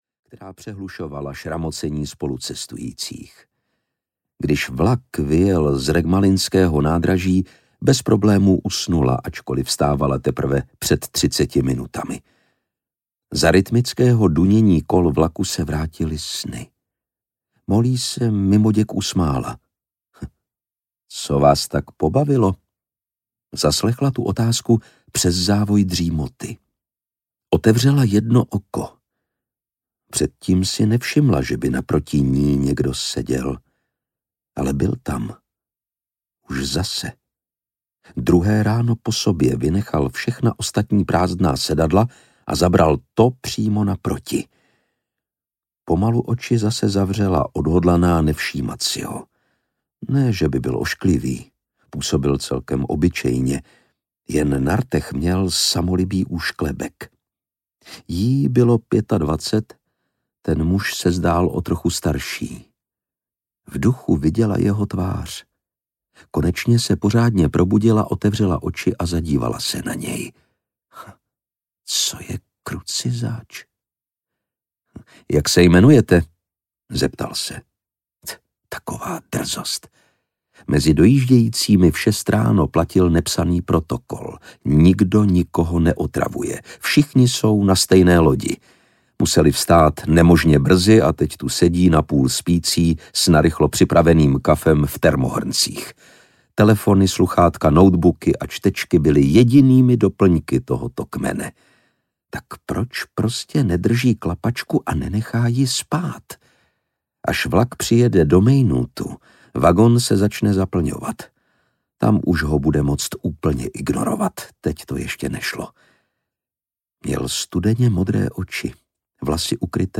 Nebezpečná místa audiokniha
Ukázka z knihy
• InterpretLukáš Hlavica